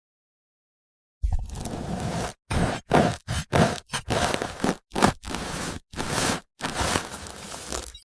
Burger ASMR🤣